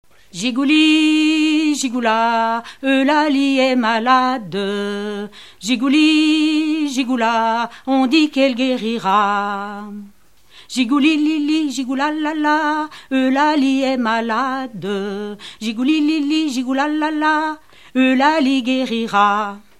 Vouillé-les-Marais
Enfantines - rondes et jeux
Pièce musicale inédite